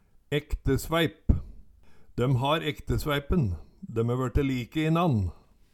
Sagt om ektepar som vert like å sjå på, etter kvart som dei vert eldre Høyr på uttala